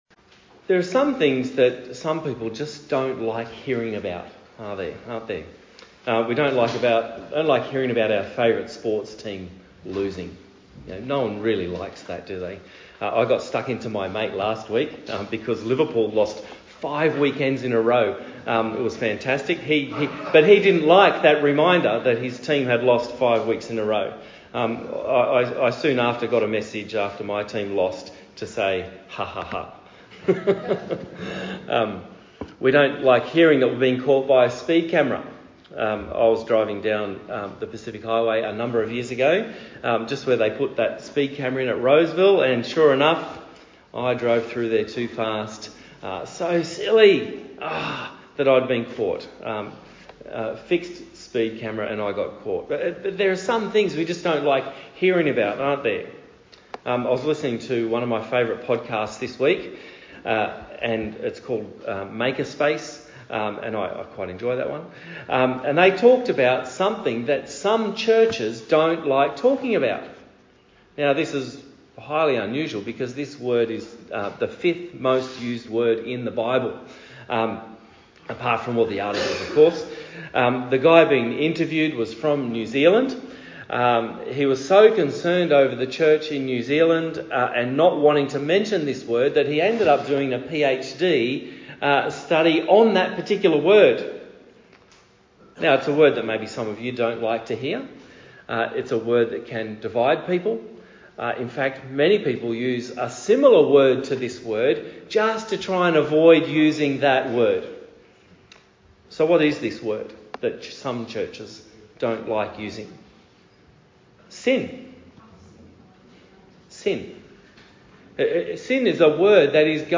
Outline (see PDF for slides to download with this sermon)